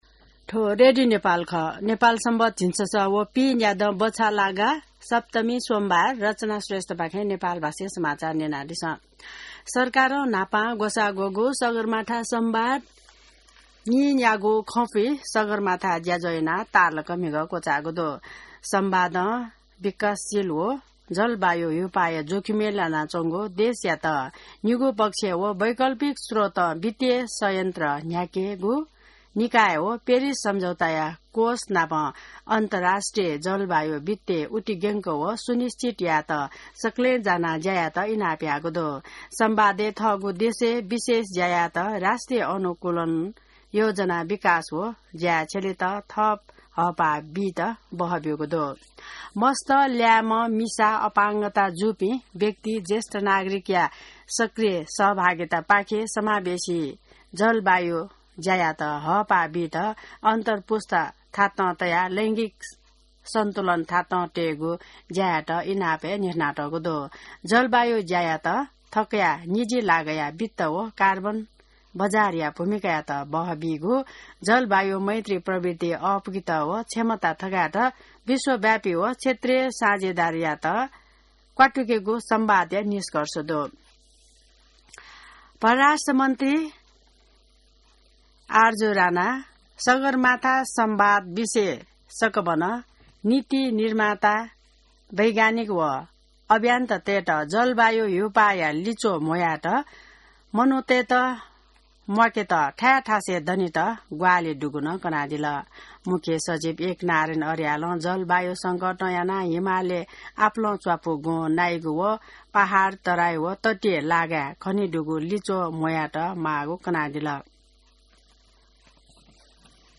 नेपाल भाषामा समाचार : ५ जेठ , २०८२